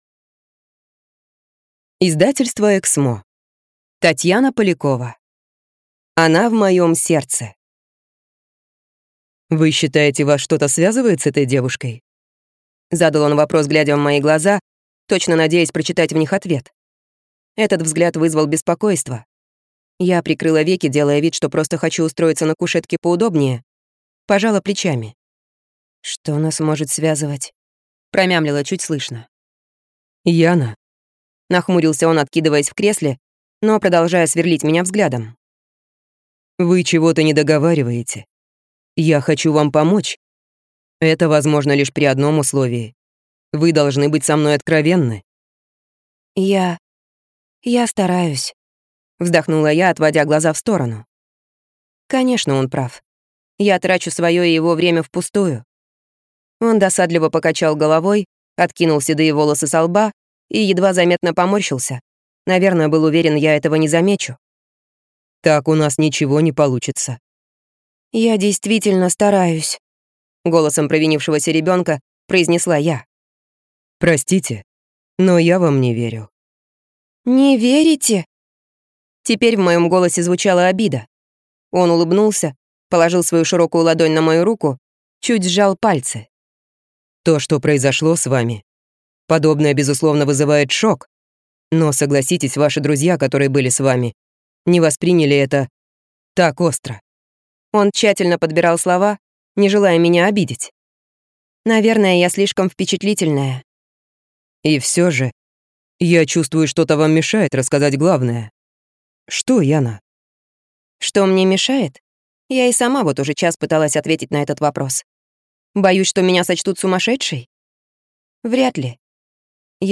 Аудиокнига Она в моем сердце | Библиотека аудиокниг